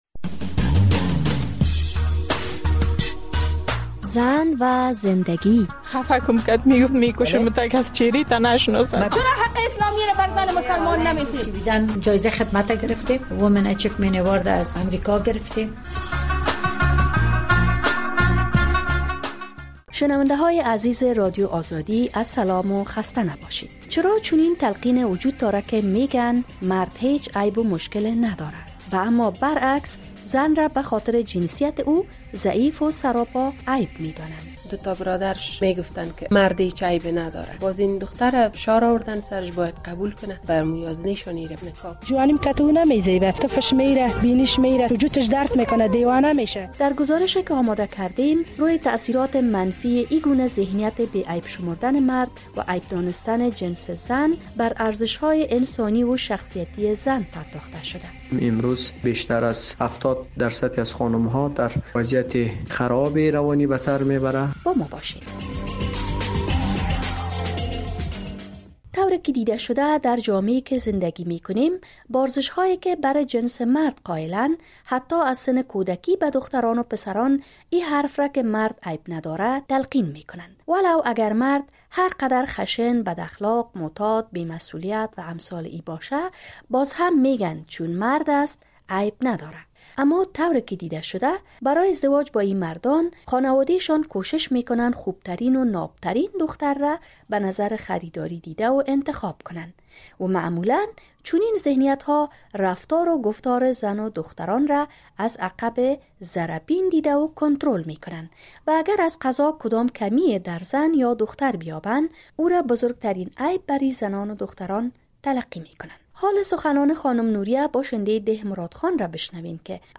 در گزارشی که آماده کردیم روی تاثیرات منفی این‌گونه ذهنیت بی عیب شمردن مرد و عیب دانستن جنس زن، بر ارزش‌های انسانی و شخصیتی زن پرداخته شده است.